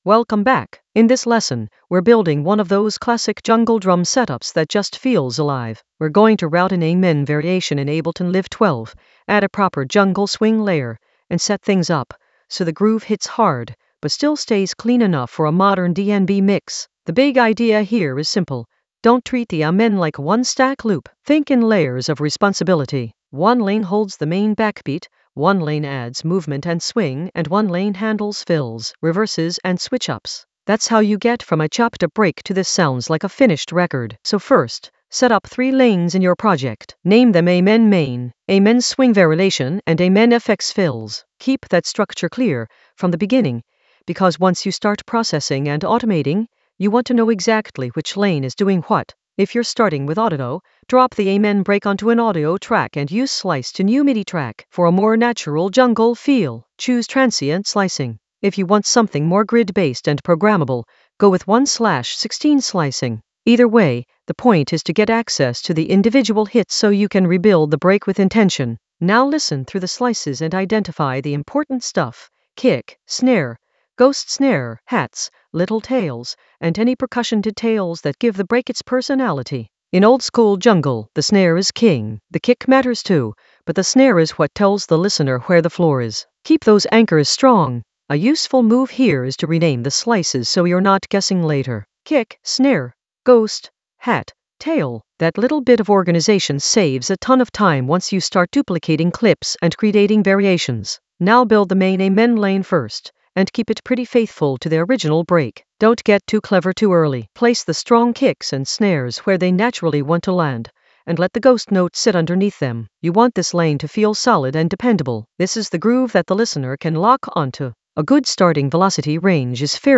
An AI-generated intermediate Ableton lesson focused on Route an amen variation with jungle swing in Ableton Live 12 for jungle oldskool DnB vibes in the Drums area of drum and bass production.
Narrated lesson audio
The voice track includes the tutorial plus extra teacher commentary.